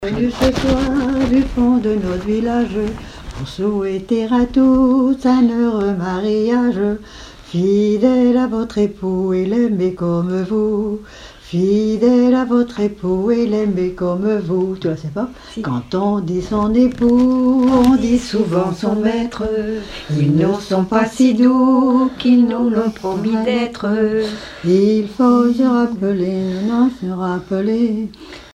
circonstance : fiançaille, noce
Chansons en français
Pièce musicale inédite